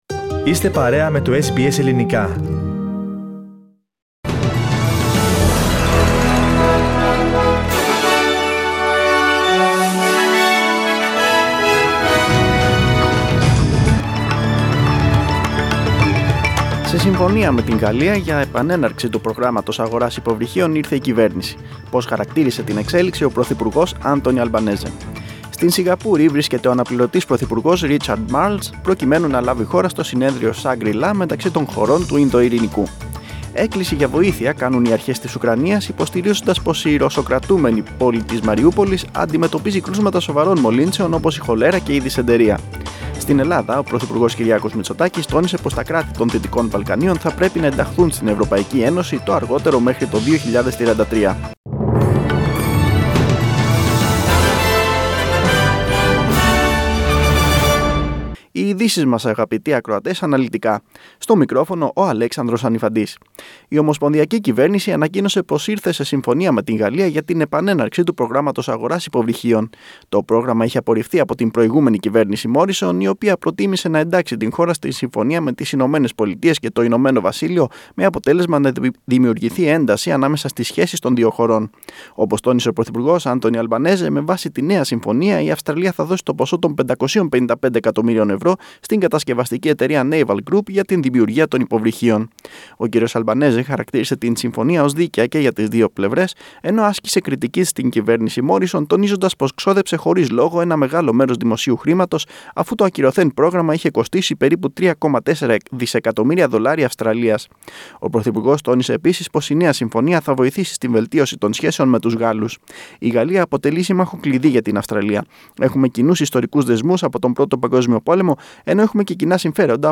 Δελτίο Ειδήσεων 11.6.2022